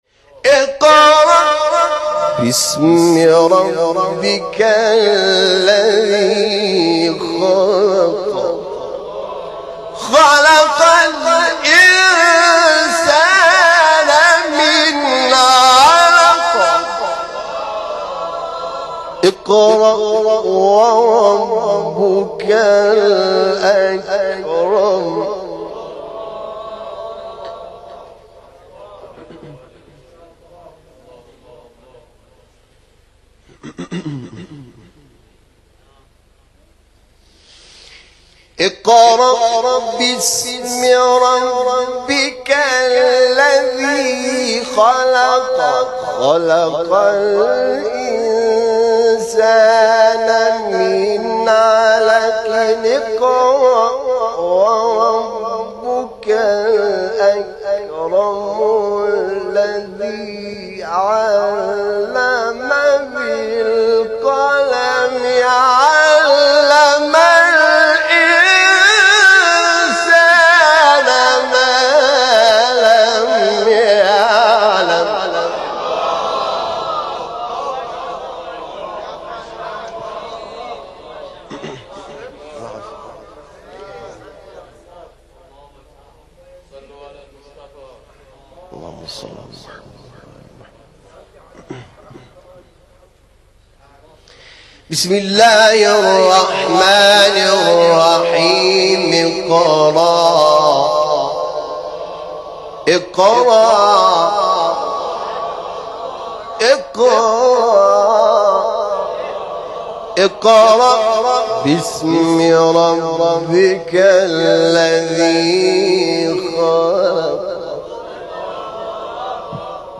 تلاوت 1-5 سوره علق استاد حامد شاکرنژاد | نغمات قرآن
سوره : علق آیه : 1-5 استاد : حامد شاکرنژاد مقام : رست قبلی بعدی